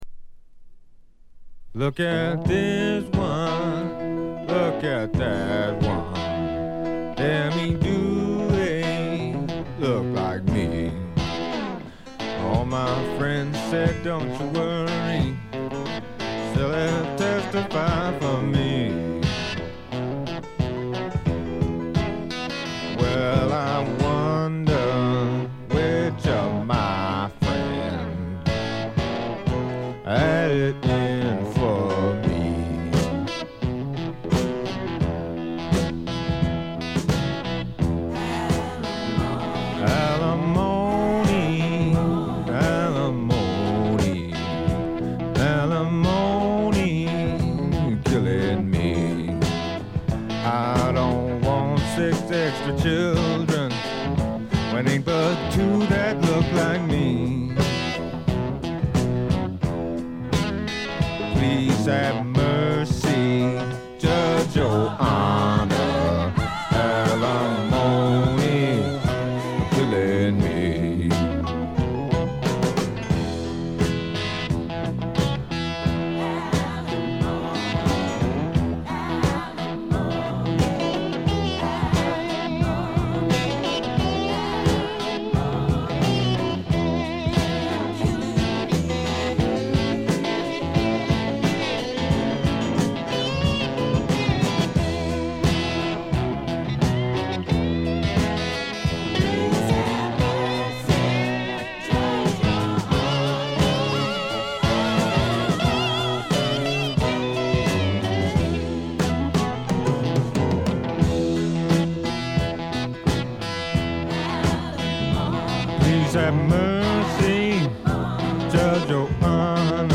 ほとんどノイズ感無し。
試聴曲は現品からの取り込み音源です。
Guitar, Mandolin, Bass